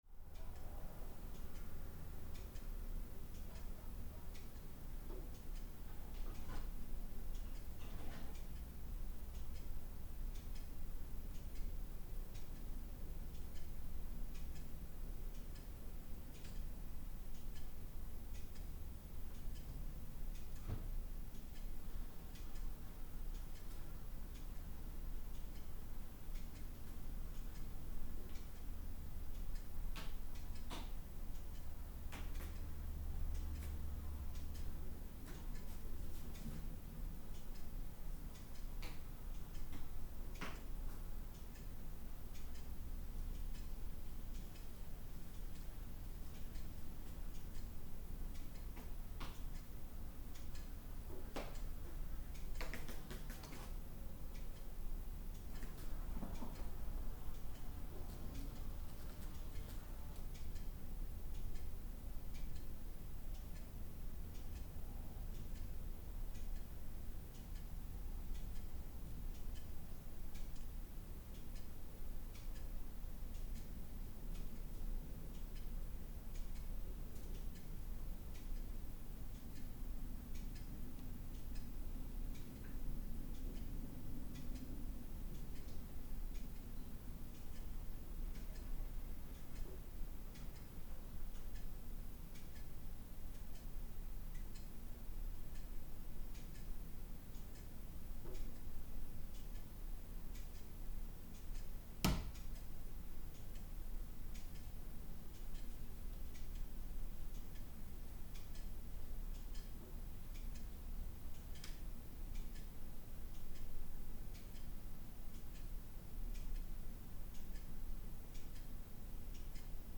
This recordings was made in 50m2 garage in the countryside. This recording contains mainly two ticking clocks, both sides of the mic rig, also a buzzing fly and a mouse jumping somewhere in the garage. Outside is a traffic in a distance
Same audio samples again but with +24dB added gain to the original recording, combined 74dB of gain. *
Sennheier MKH8040